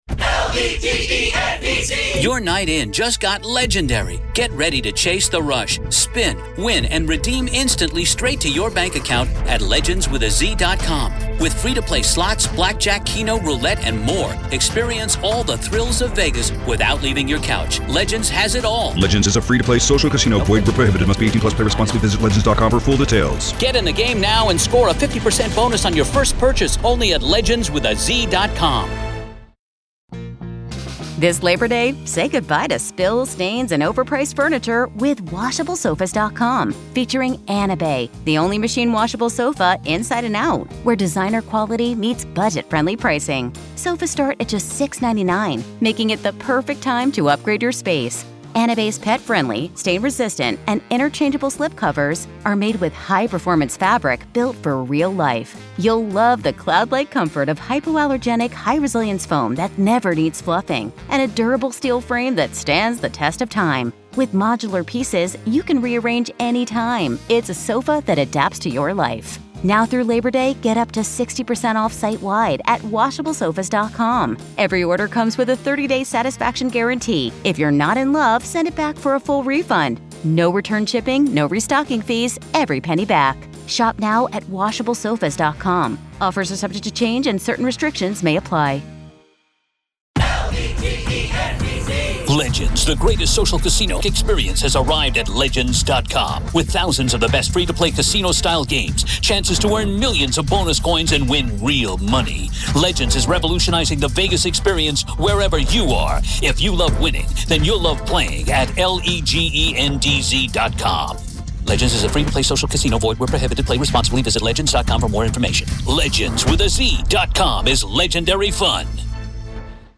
The conversation also touches on the limits of legal intervention once a case is closed, and the bigger question: what does it say about our system that inmate safety decisions are made almost entirely behind closed doors?